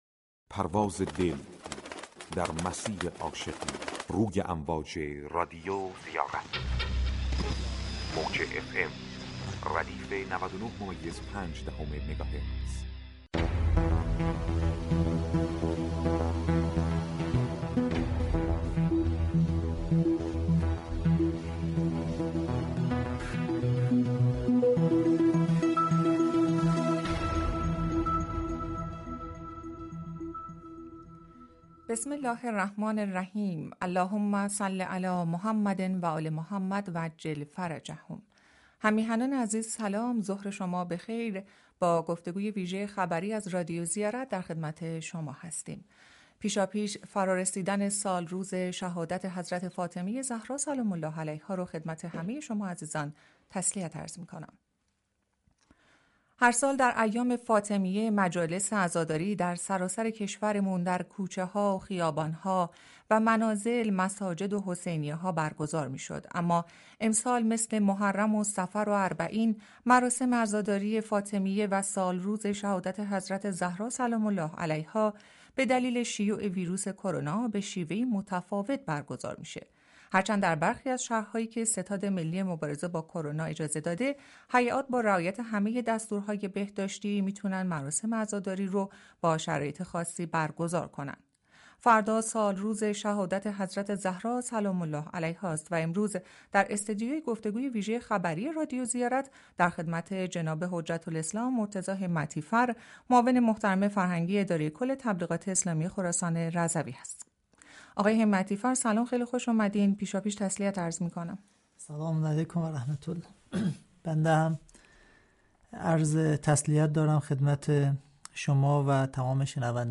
گفتگوی ویژه خبری رادیو زیارت با مهمانان این برنامه